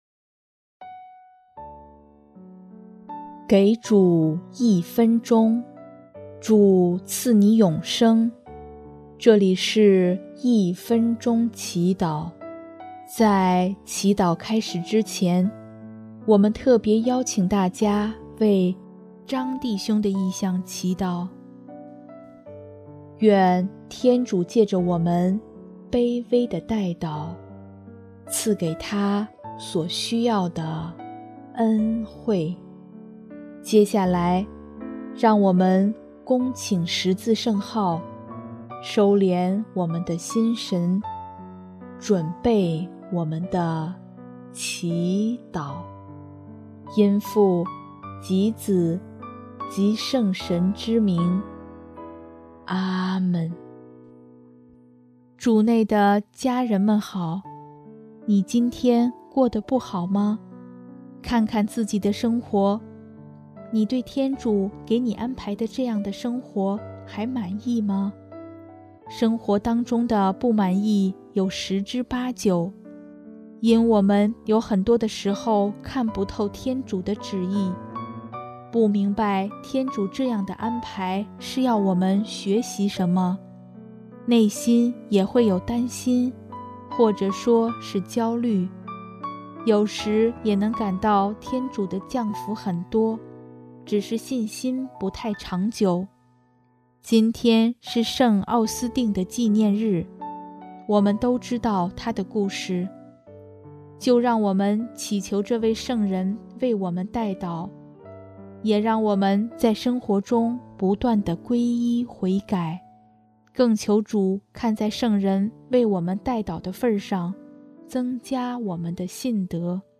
【一分钟祈祷】|8月28日 主求你弥补我们信德的不足